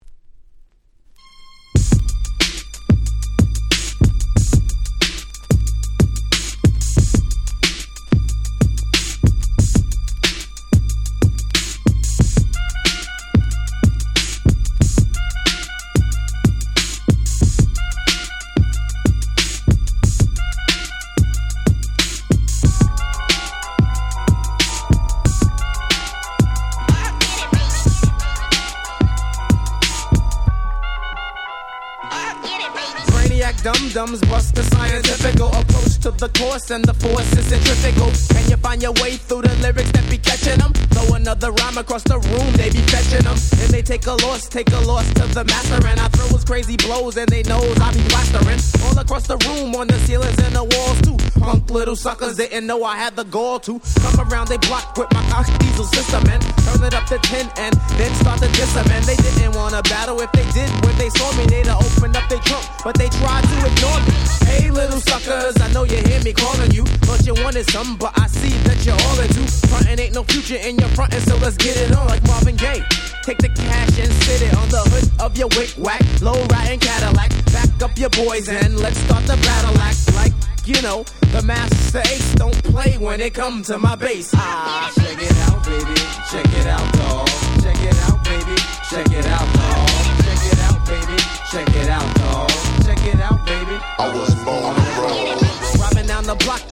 94' Super Nice Hip Hop !!